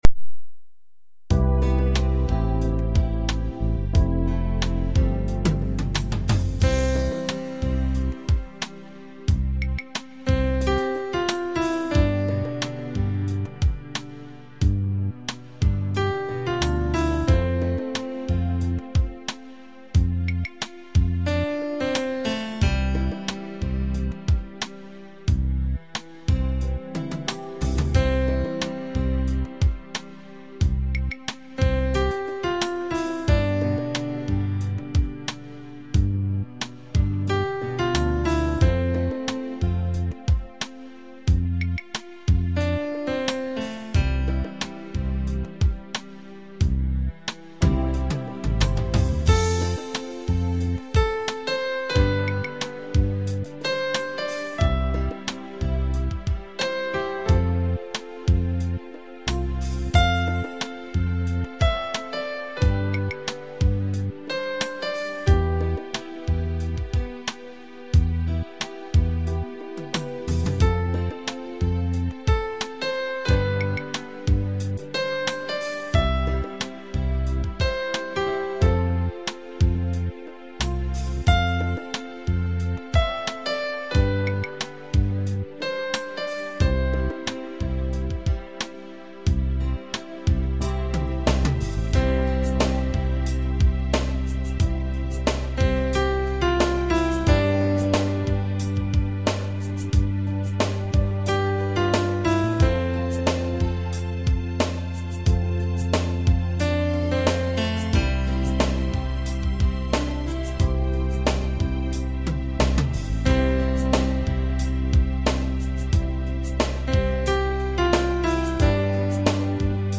Slow Rock